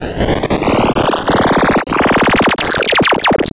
Glitch FX 35.wav